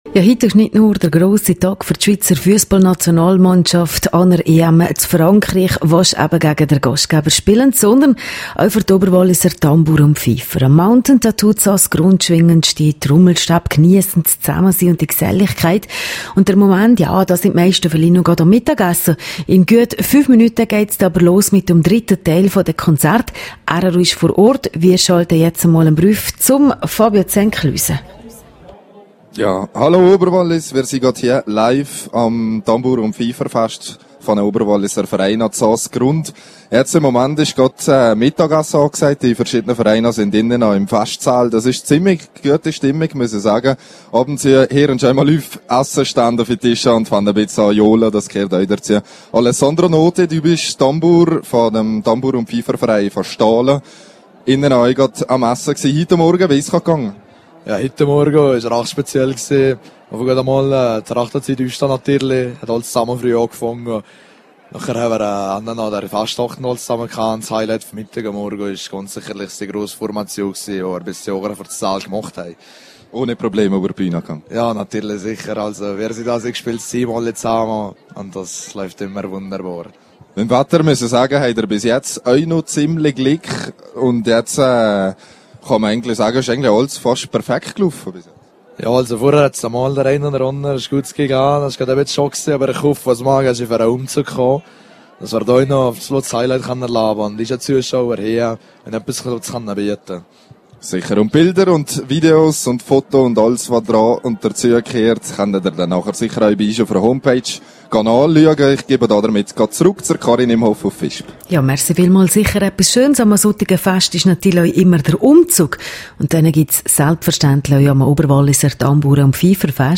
Am Wochenende trafen sich in Saas-Grund 26 Oberwalliser Tambouren- und Pfeifervereine zum Fest. Grosser Publikumsaufmarsch und beste Stimmung waren garantiert.
(Quelle: rro) rro am Oberwalliser Tambouren- und Pfeiferfest (Quelle: rro)